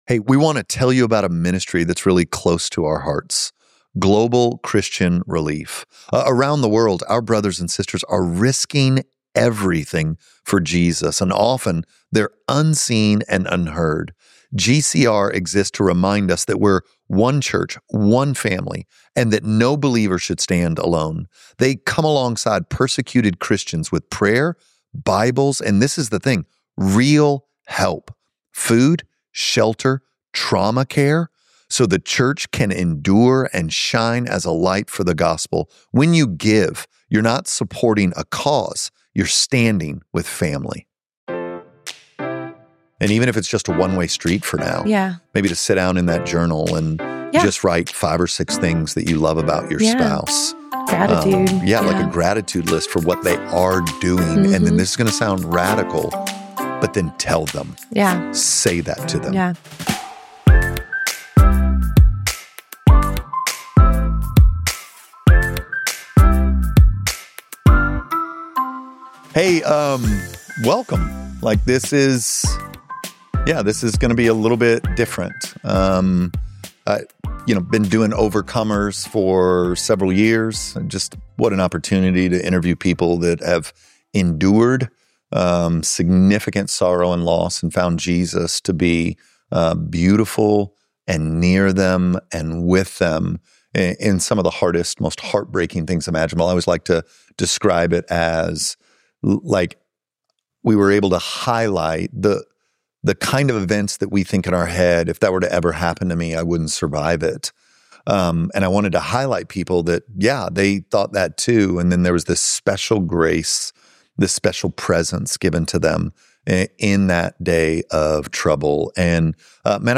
We are pulling up two chairs and inviting you into the kinds of conversations we’ve had over two decades of marriage—some tender, some funny, all rooted in grace and truth.